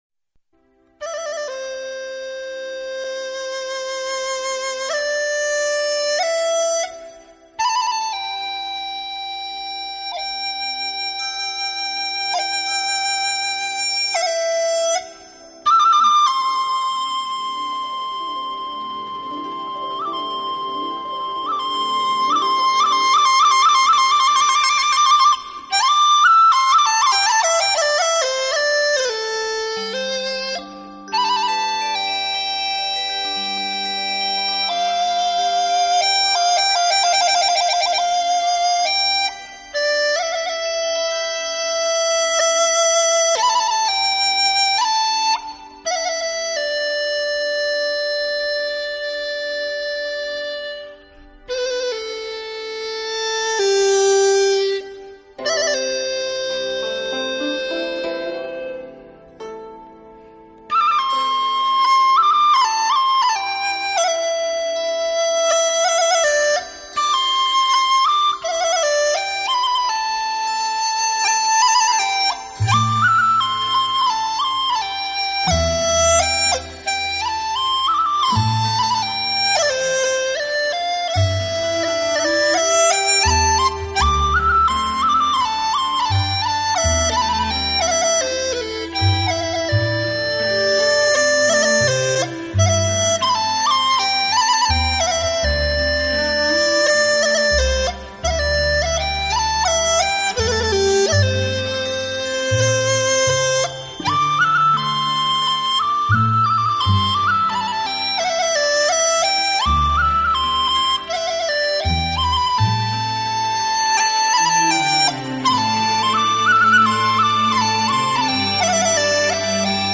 主要演奏乐器：笛子演奏